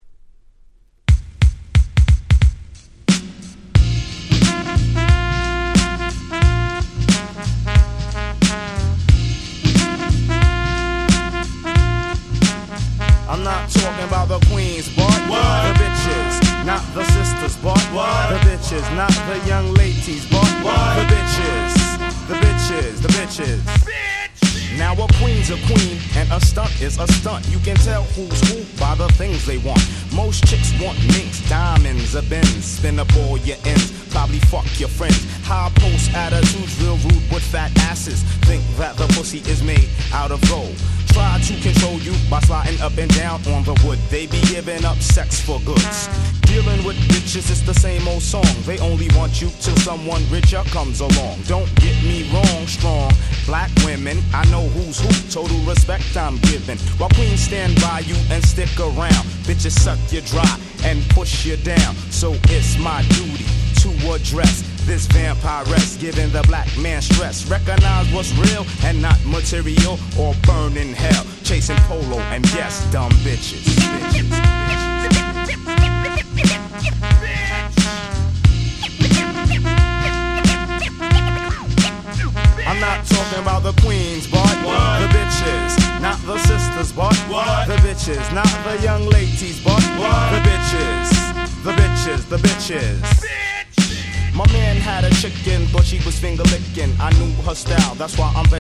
95' Super Nice Hip Hop !!